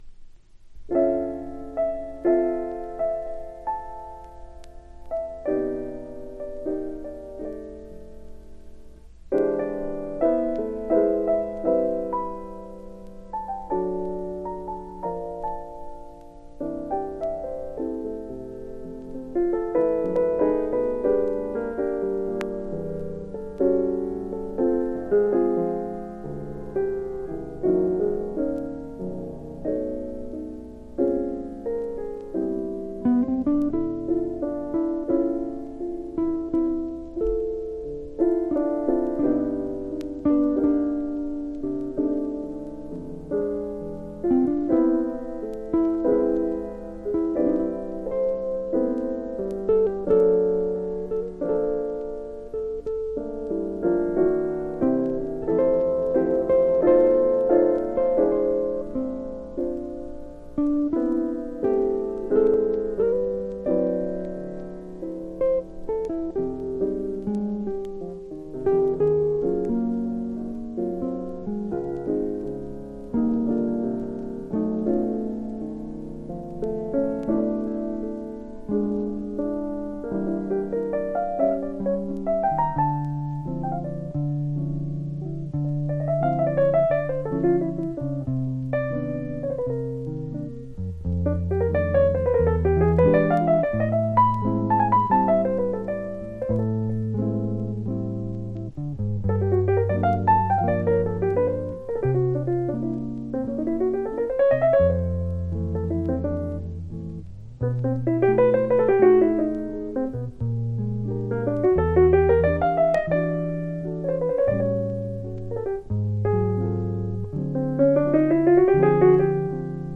（小傷によりチリ、プチ音ある曲あり）※曲名を…